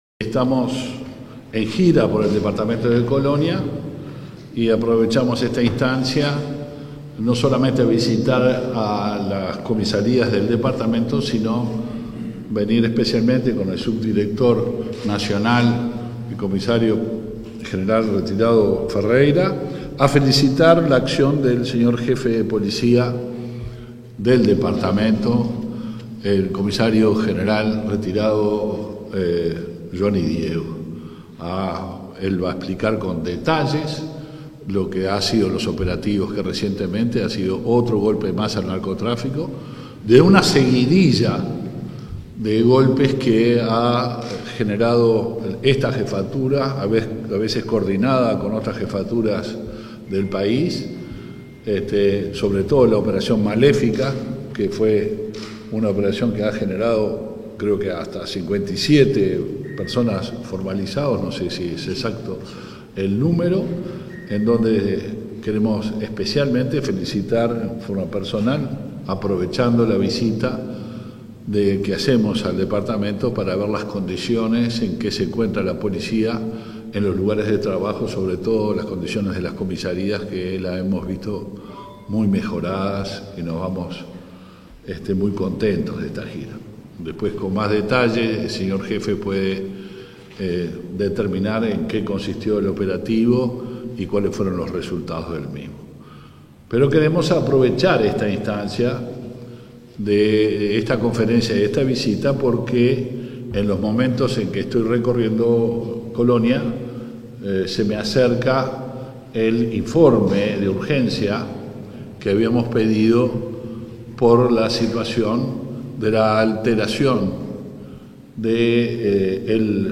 Palabras del ministro del Interior, Luis Alberto Heber
Palabras del ministro del Interior, Luis Alberto Heber 30/09/2022 Compartir Facebook X Copiar enlace WhatsApp LinkedIn Tras la recorrida por las dependencias policiales en el departamento de Colonia, este 30 de setiembre, el ministro del Interior, Luis Alberto Heber, se expresó en conferencia de prensa.